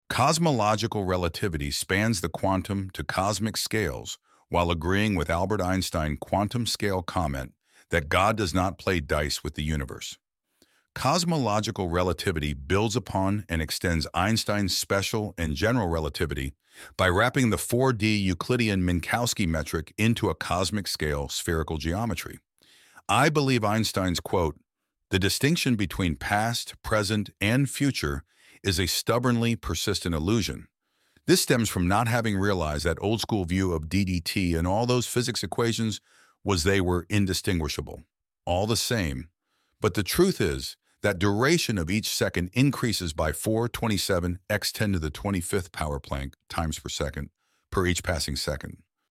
file) 768 KB Category:AI voice Category:AI 1
Comments_on_two_Einstein_quotes_elevenlabs_speech_Brian.mp3